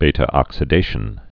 (bātə-ŏksĭ-dāshən, bē-)